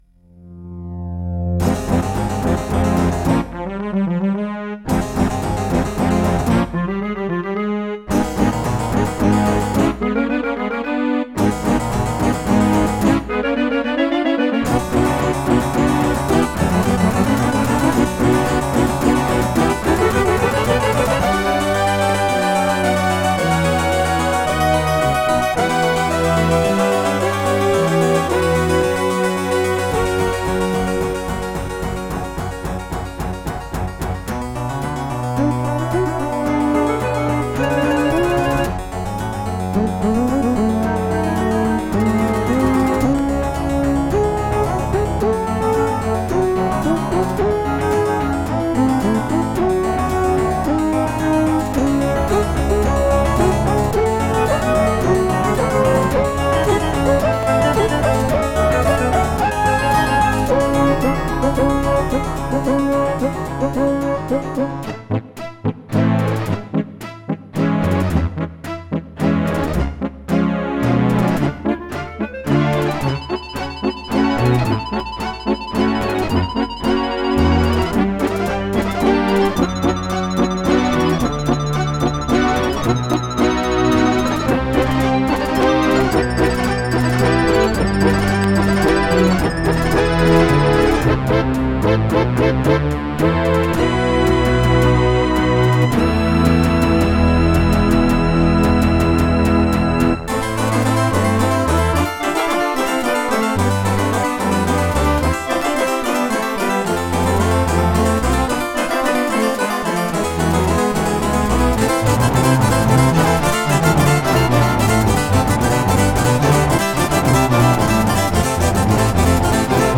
Roland LAPC-I
* Some records contain clicks.